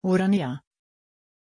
Pronunciation of Orania
pronunciation-orania-sv.mp3